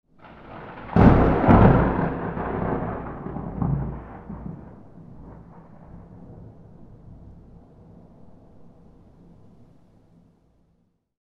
Lightning Strike Sound Effect
Description: Lightning strike sound effect. Powerful thunderclap with sharp lightning crack, perfect for cinematic scenes, video games, horror moments, and stormy atmospheres.
Lightning-strike-sound-effect.mp3